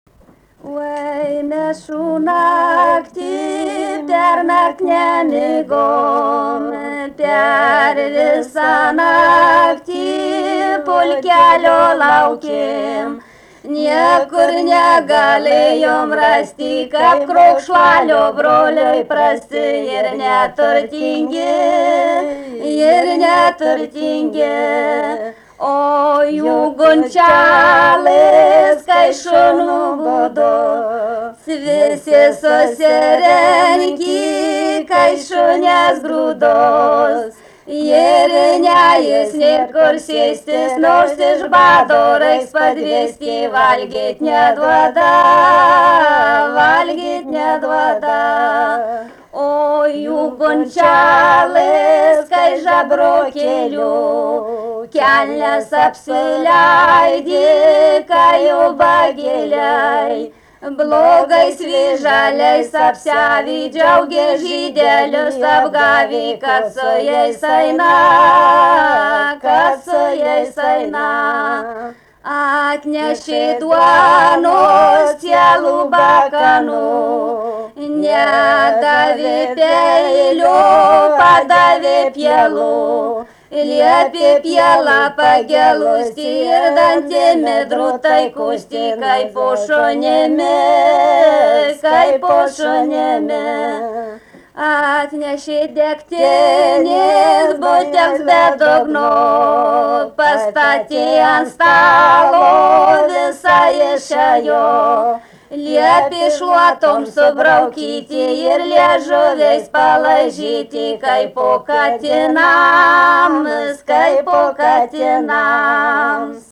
vokalinis
2 balsai